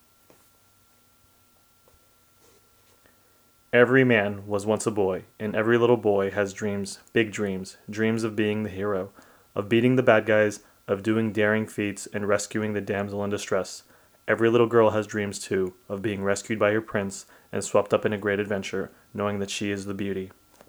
Will try my own ACX-test shortly in the evening, I ran out of time and did a quick sound test again to not have peaks.
The clip has perfect sound levels.
The last clip has [shuffle, shuffle, snort, snick, snick] “Every man was once…” I’m not supposed to be able to tell you were adjusting your shirt or settling your pants during that two seconds.